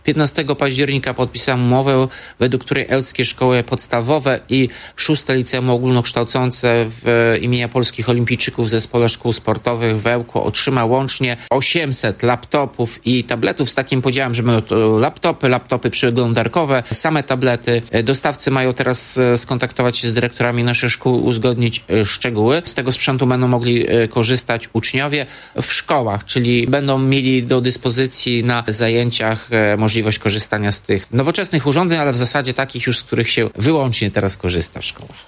To w ramach wsparcia z Krajowego Planu Odbudowy i Zwiększania Odporności, mówi Tomasz Andrukiewicz prezydent Ełku.